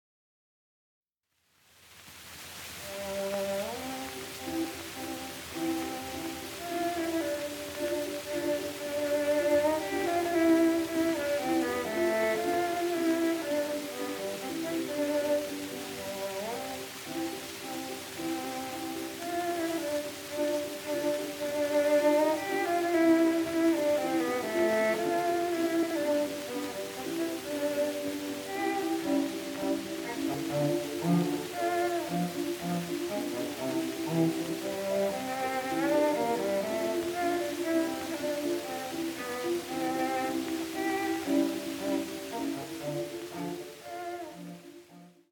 古い録音で聴くチェロの響きの奥深さよ。
録音：1916〜20年　モノラル録音